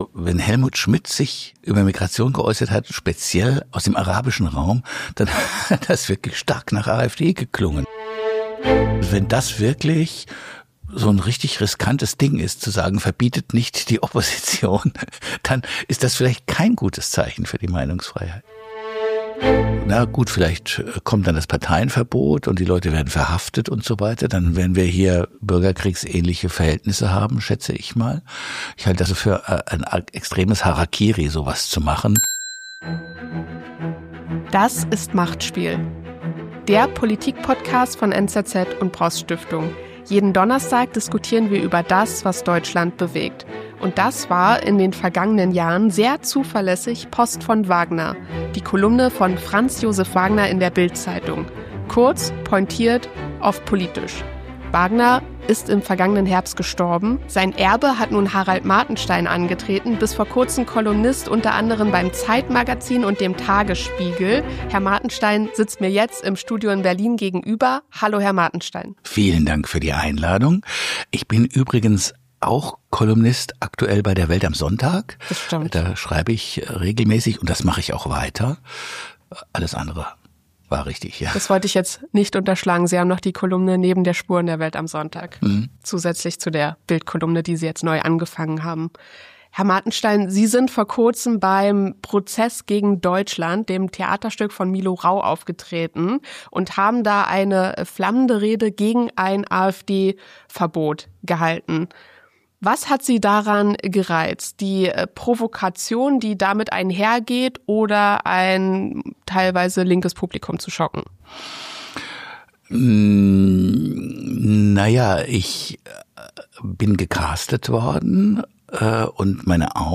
Gast: Harald Martenstein, Kolumnist unter anderem bei "BILD" und "Welt am Sonntag"